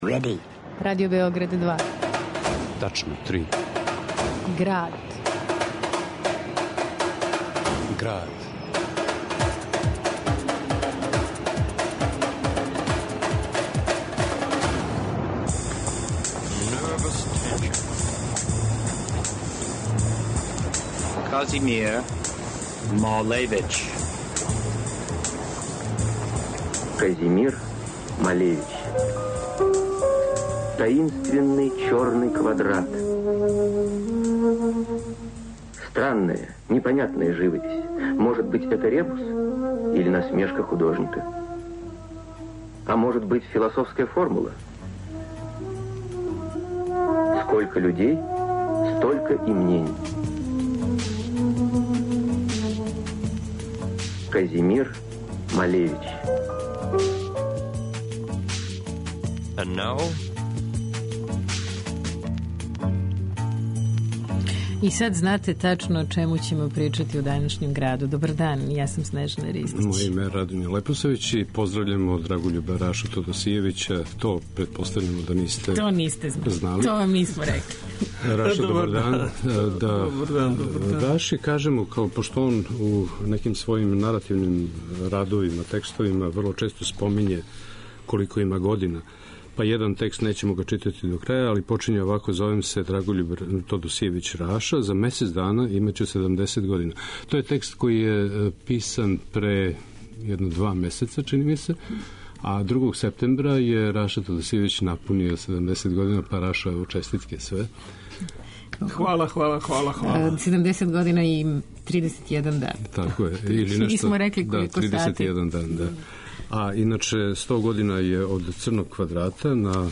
на архивским снимцима